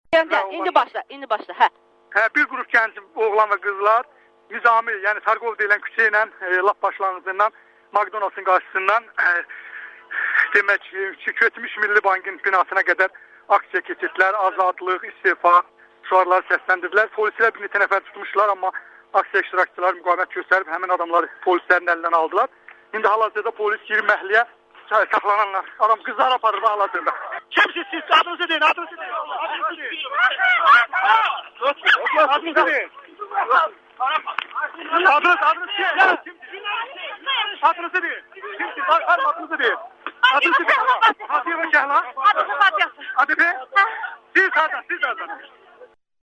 AzadlıqRadiosunun müxbiri hadisə yerindən xəbər verir